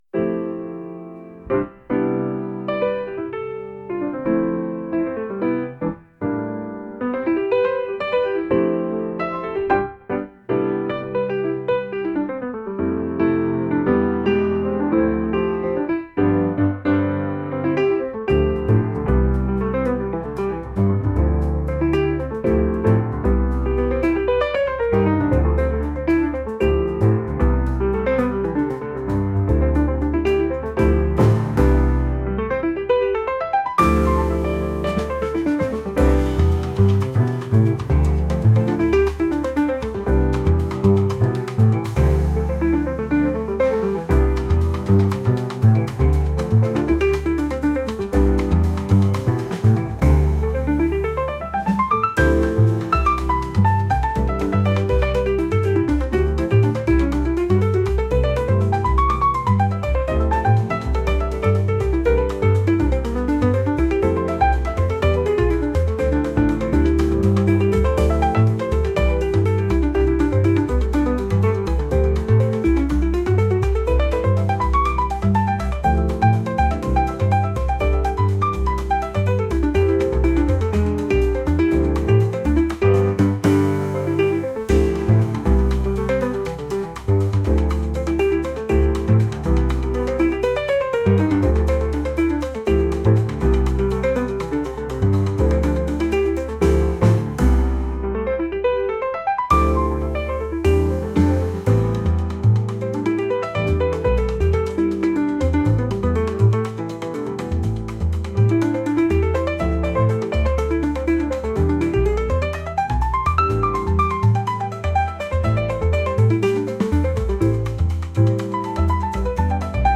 ピアノメインのジャズ曲です。